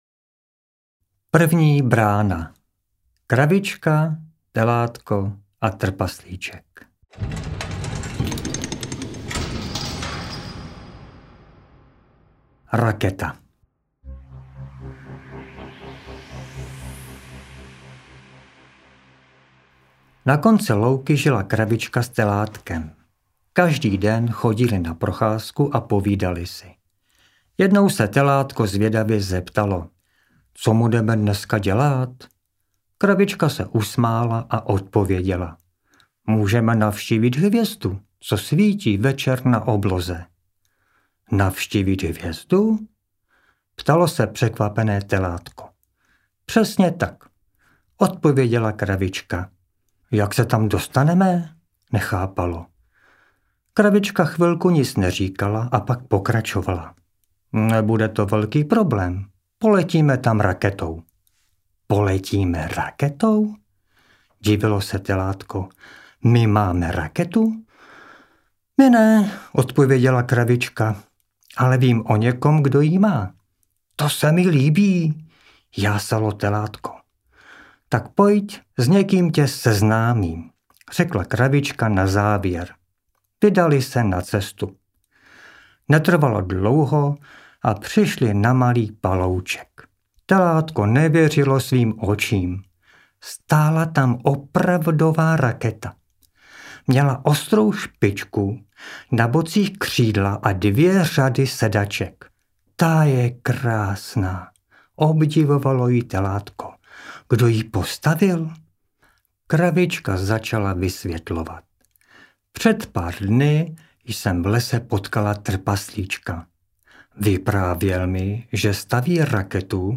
Pohádkové brány audiokniha
Ukázka z knihy